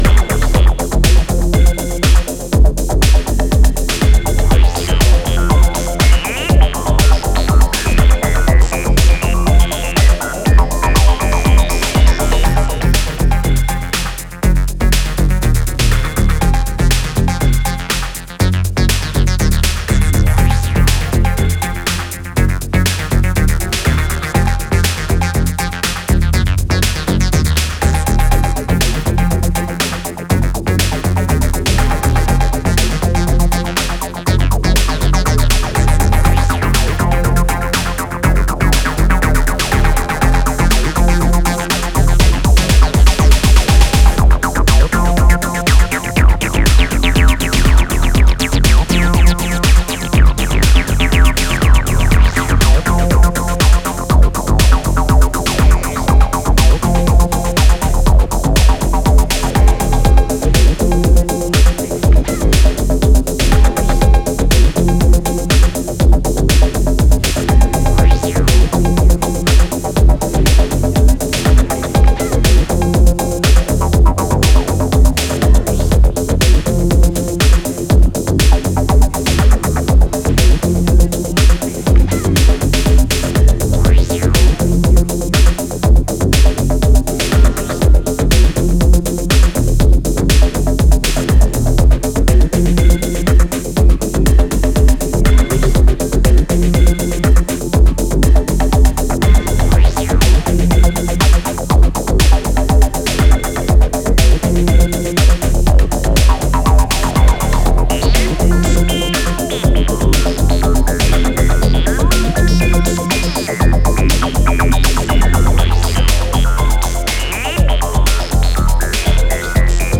trace a hypnotic journey